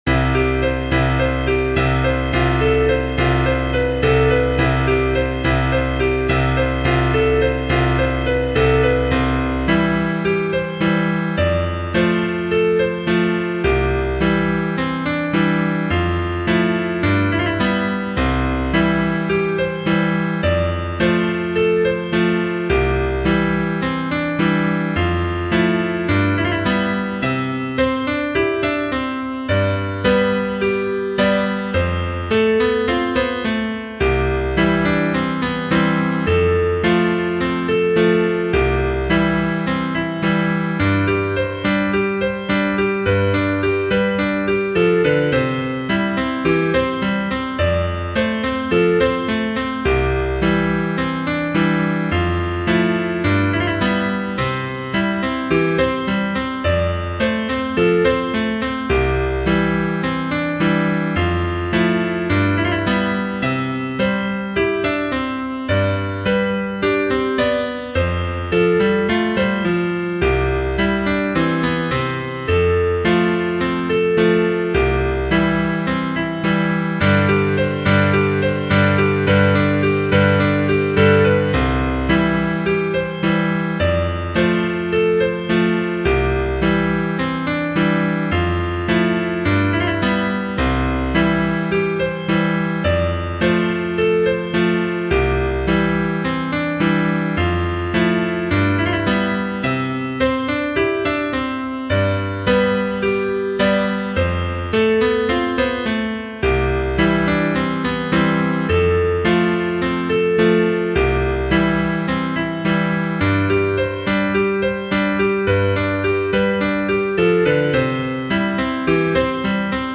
Partitura para piano / Piano score (pdf)